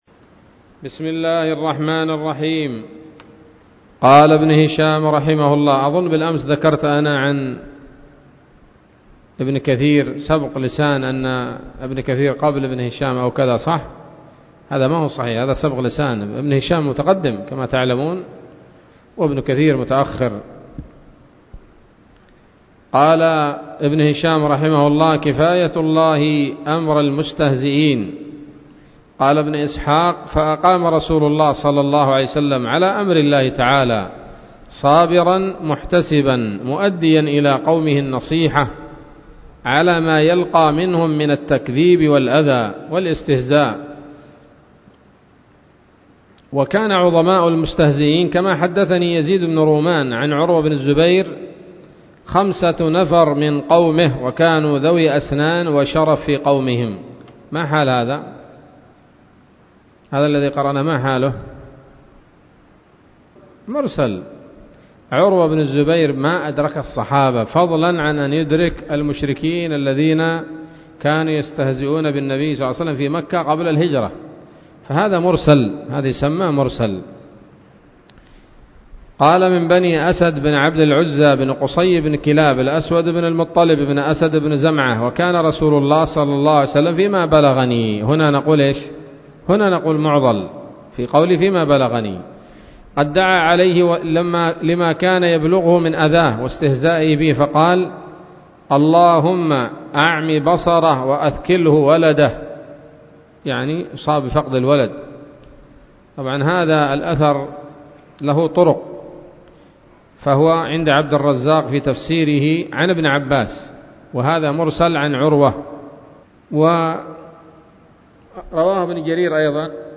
الدرس الثاني والخمسون من التعليق على كتاب السيرة النبوية لابن هشام